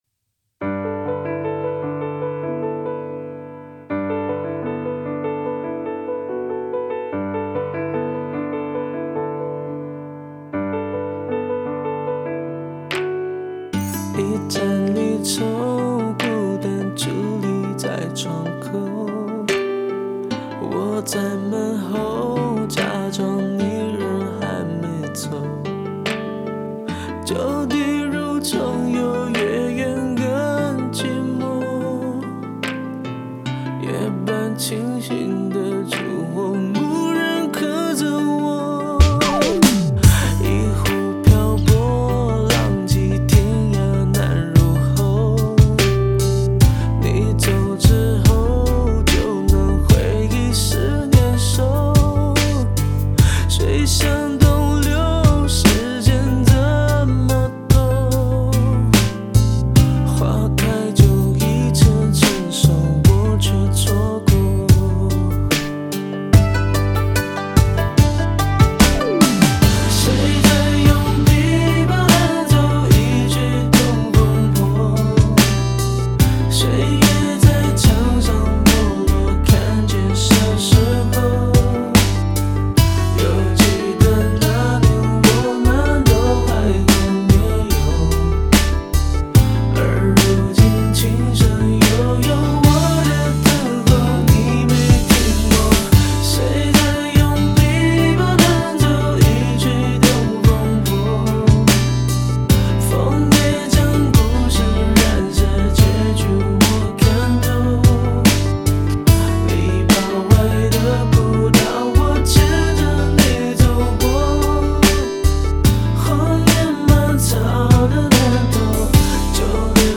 3周前 华语音乐 9